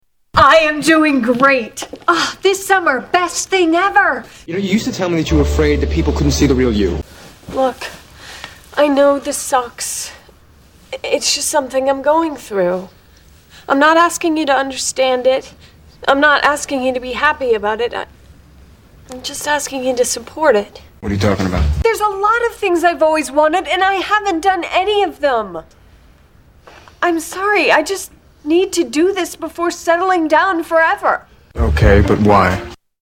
Tags: Soundboards Talk to each other Mash up Mash up clips Mash up sounds Soundboard talking